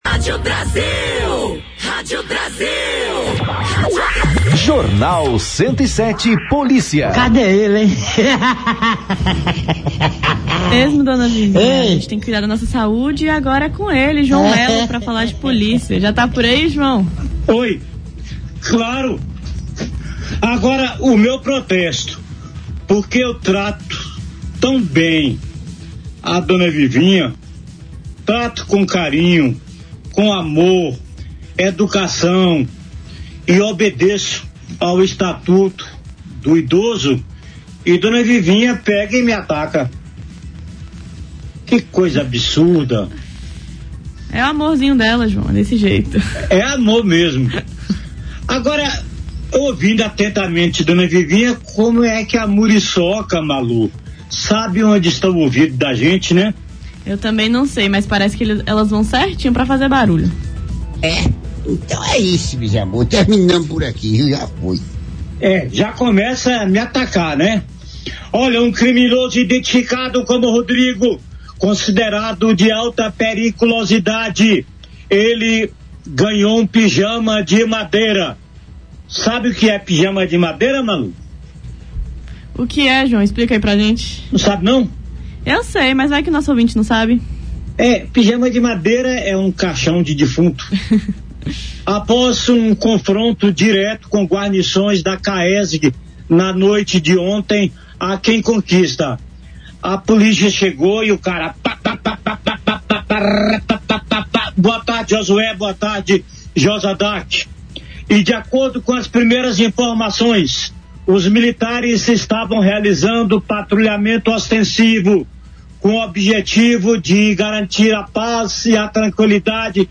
Comentário no Jornal 107 | Viação Novo Horizonte mantém operações e recebe apoio em Vitória da Conquista